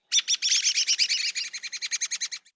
48d440e14c Divergent / mods / Soundscape Overhaul / gamedata / sounds / ambient / soundscape / swamp / sfx_8.ogg 72 KiB (Stored with Git LFS) Raw History